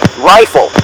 RifleE.ogg